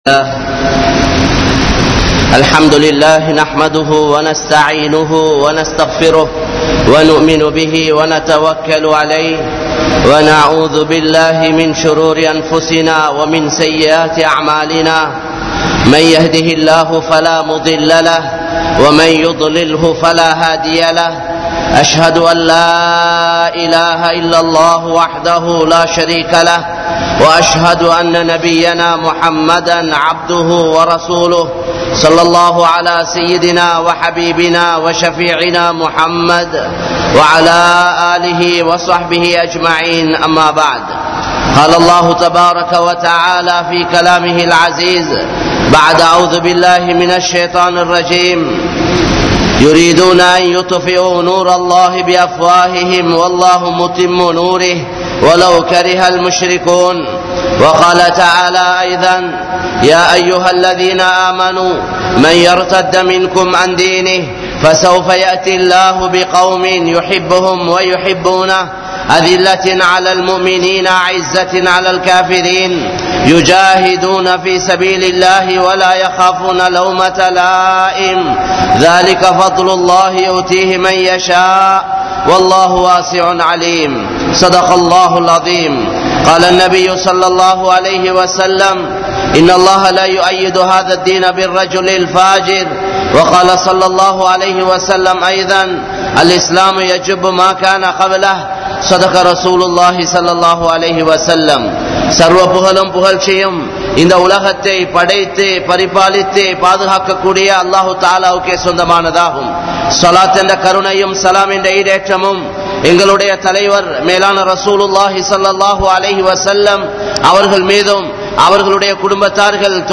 Poruppuhalai Marantha Samooham (பொறுப்புகளை மறந்த சமூகம்) | Audio Bayans | All Ceylon Muslim Youth Community | Addalaichenai